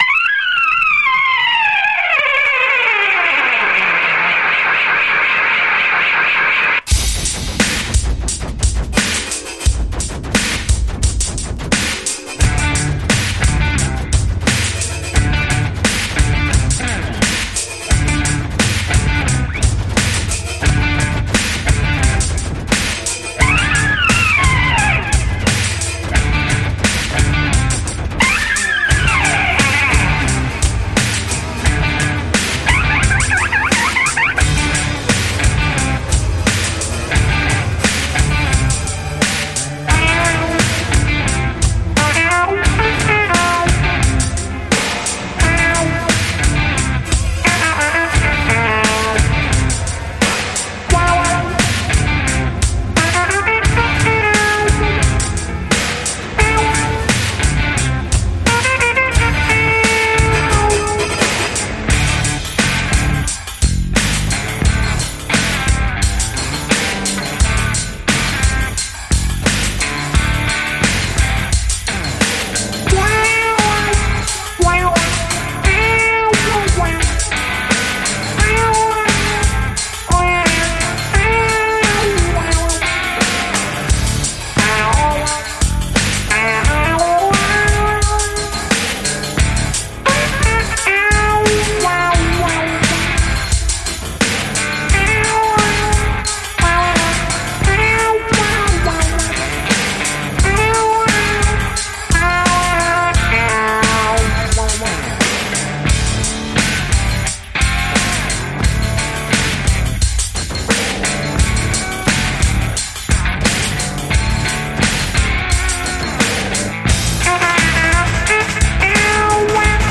マッドでスピリチュアルな空間に飛ばされる